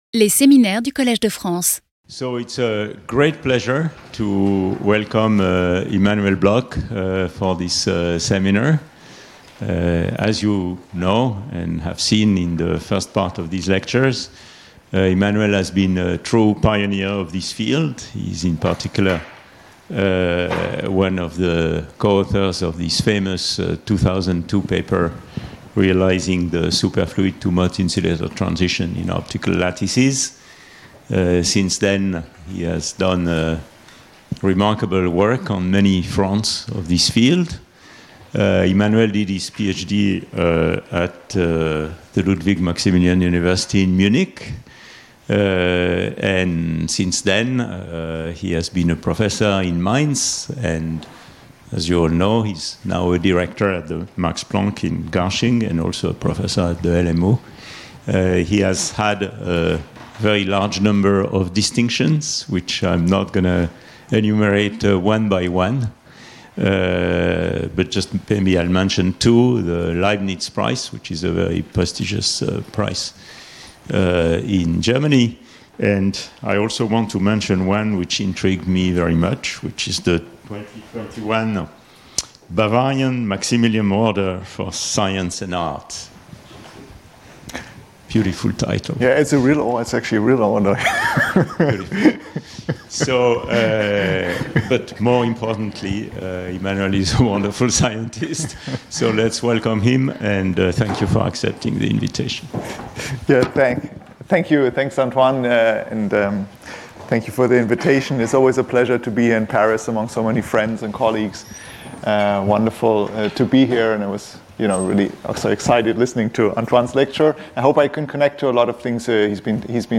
Speaker(s) Immanuel Bloch